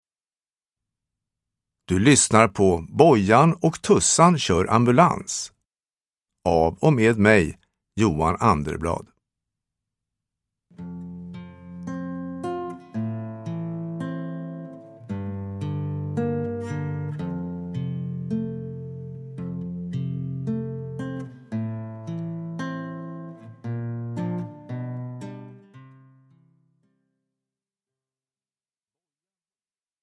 Bojan och Tussan kör ambulans – Ljudbok